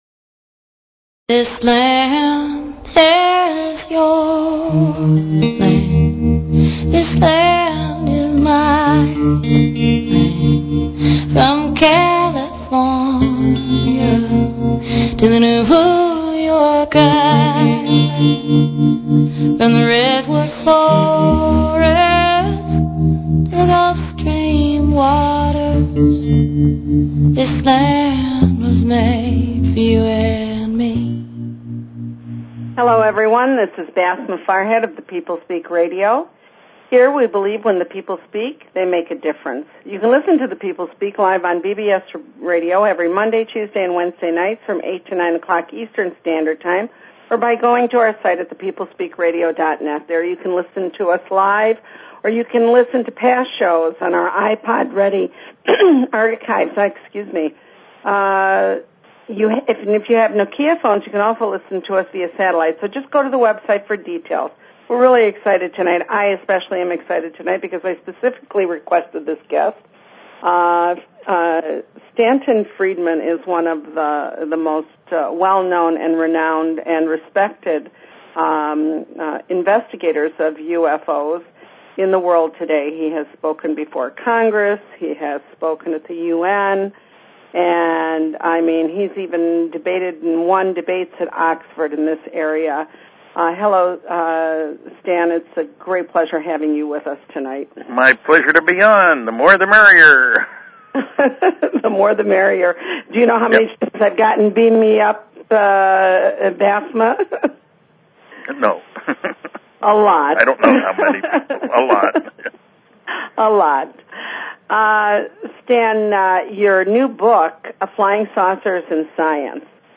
Guest, Stanton Friedman